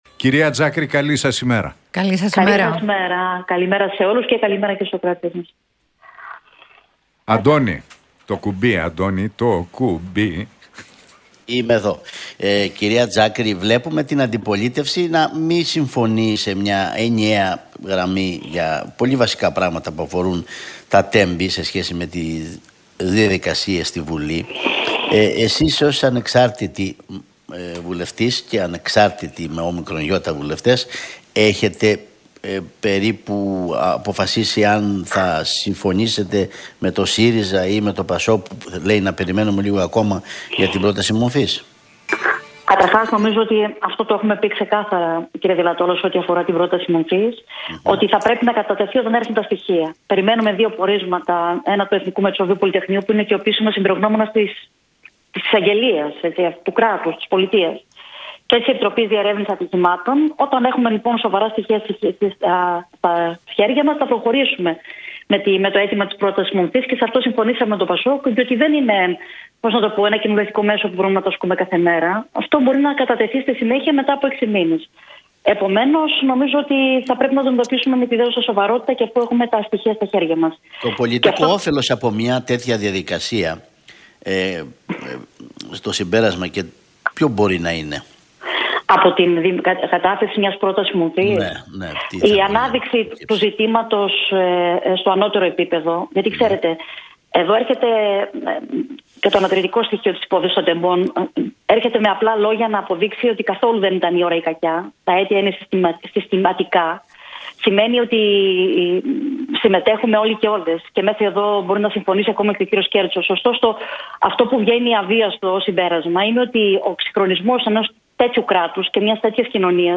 Για την υπόθεση των Τεμπών και την πρόταση σύστασης Προανακριτικής Επιτροπής από το ΠΑΣΟΚ για την τραγωδία μίλησε η ανεξάρτητη βουλευτής και μέλος του